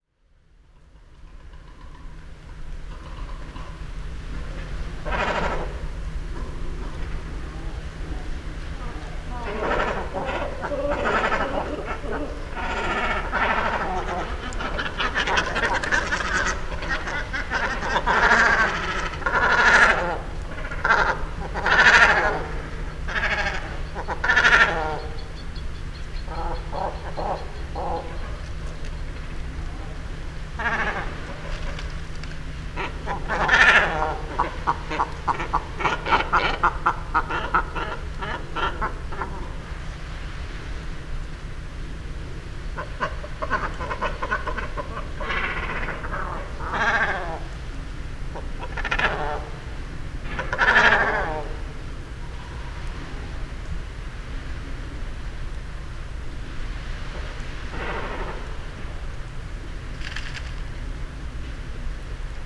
During the last years a Great Cormorant Phalacrocorax carbo roost
081217, Great Cormorant Phalacrocorax carbo, calls of flock at roost